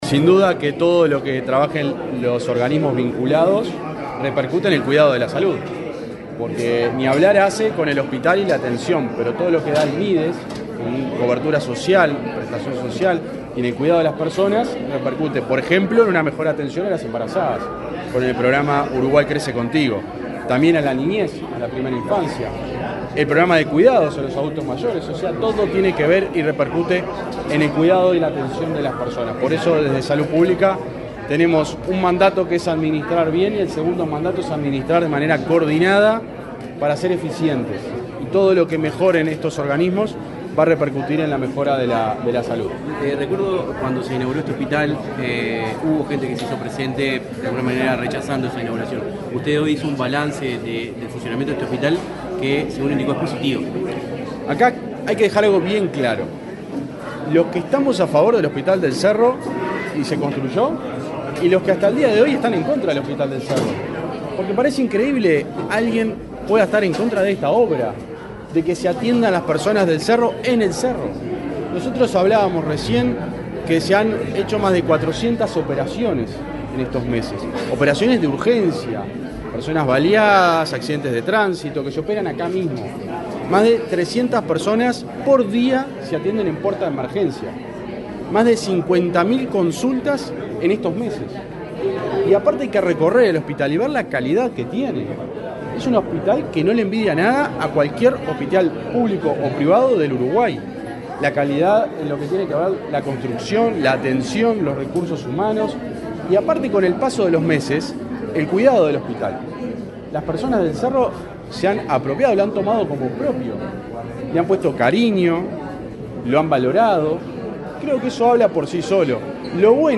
Declaraciones del subsecretario de Salud Pública
Declaraciones del subsecretario de Salud Pública 10/10/2024 Compartir Facebook X Copiar enlace WhatsApp LinkedIn El presidente de ASSE, Marcelo Sosa; el subsecretario de Salud Pública, José Luis Satdjian, y el ministro de Desarrollo Social, Alejandro Sciarra, participaron de la inauguración de una oficina territorial del Mides en el hospital del Cerro, en Montevideo. Luego, Satdjian dialogó con la prensa.